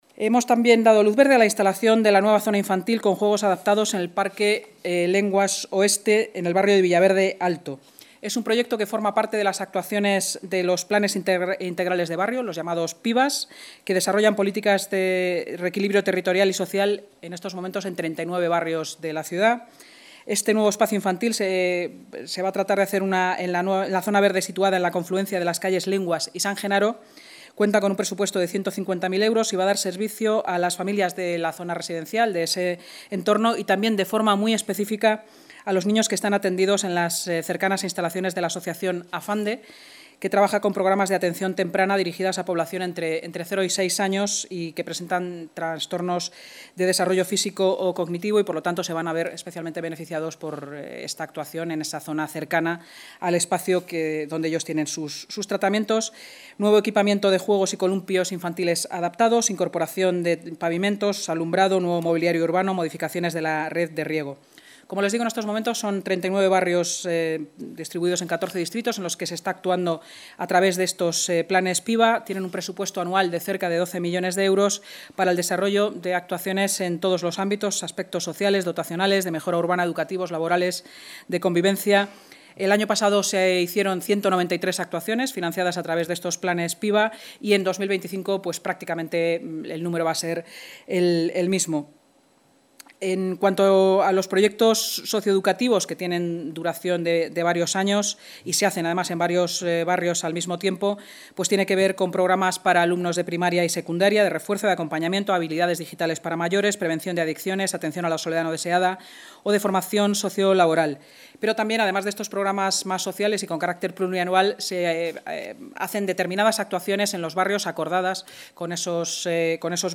Nueva ventana:Declaraciones de la vicealcaldesa de Madrid, Inma Sanz, durante la rueda de prensa, sobre la nueva instalación infantil en Villaverde Alto